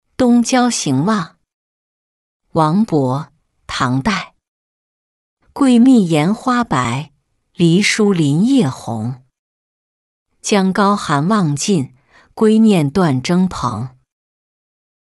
冬郊行望-音频朗读